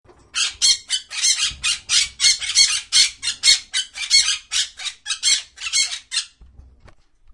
Download Parrot sound effect for free.
Parrot